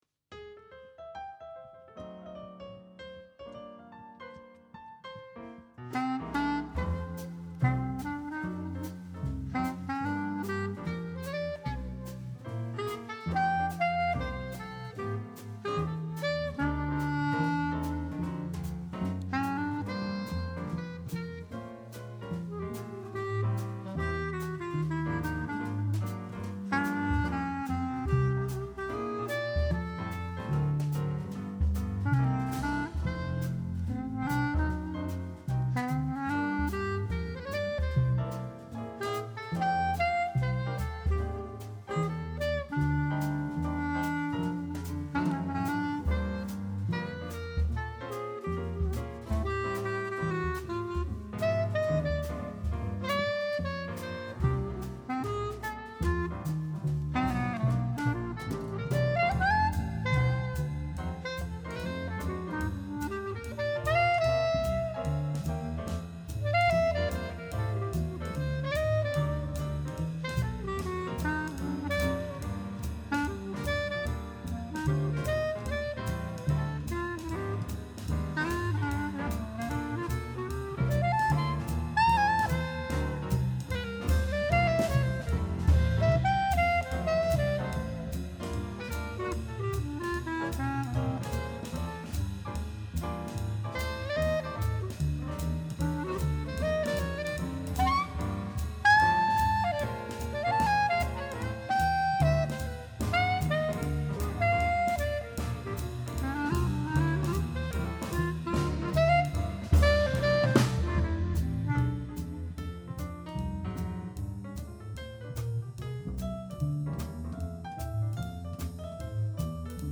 vocals
sax, tb …
piano
bass
drums
Probe vom 24.1.26